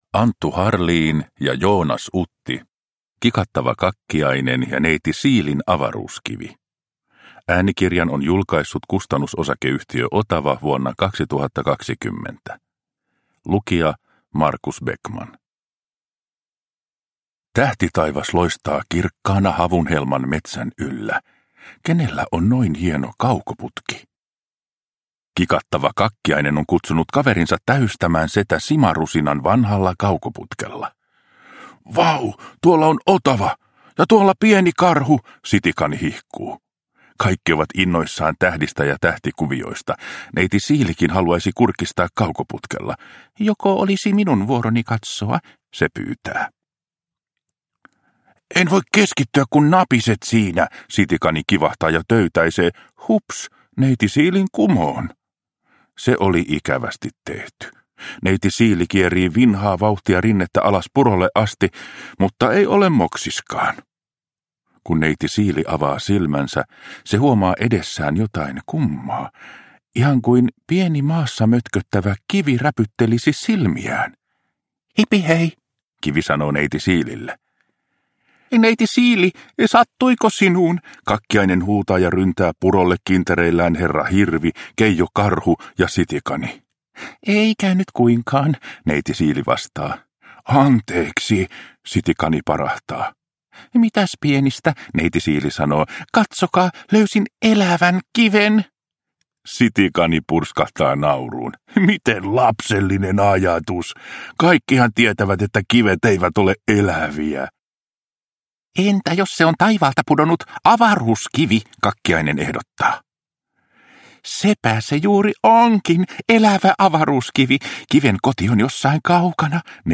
Kikattava Kakkiainen ja Neiti Siilin avaruuskivi – Ljudbok – Laddas ner